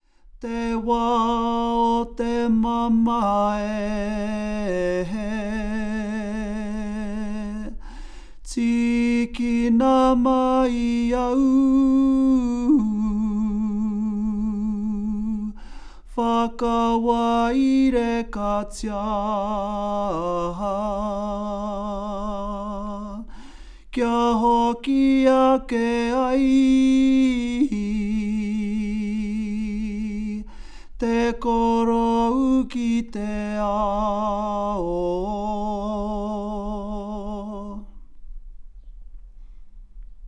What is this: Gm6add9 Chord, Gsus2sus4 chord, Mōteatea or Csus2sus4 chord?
Mōteatea